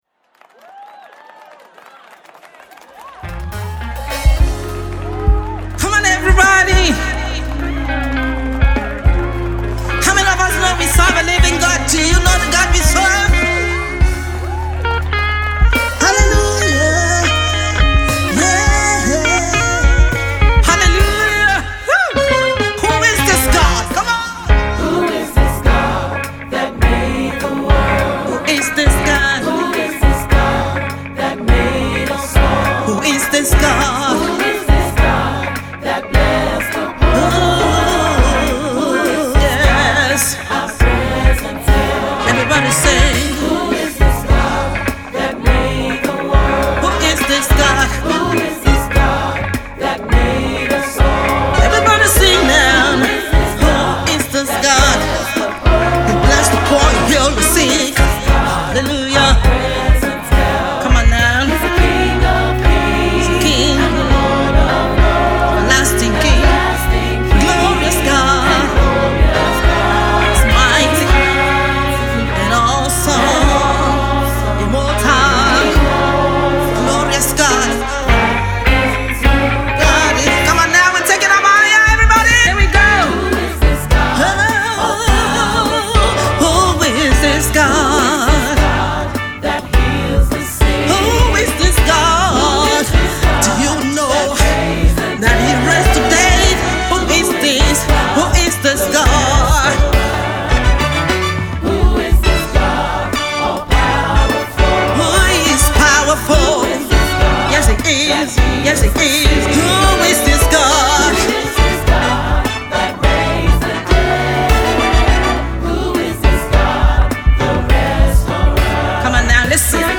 United kingdom based Gospel Artist
She delivers the year’s most uplifting and honest songs